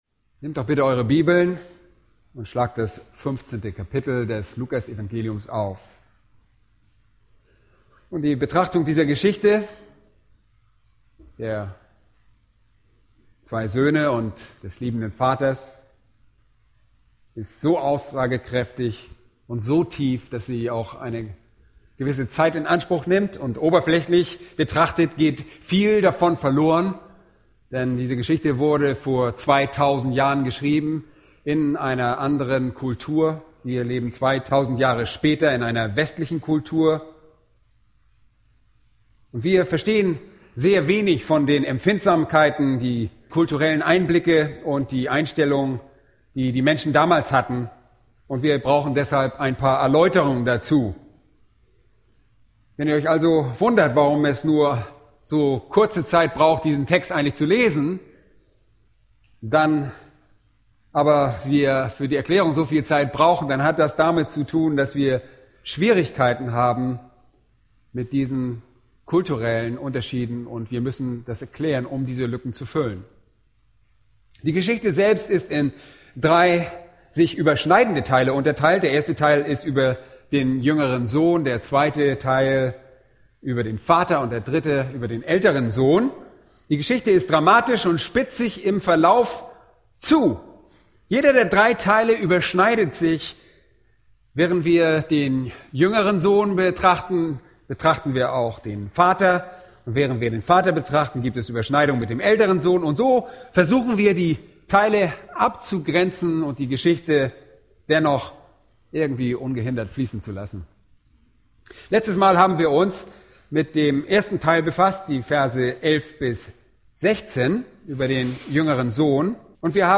Eine predigt aus der serie "Weitere Predigten."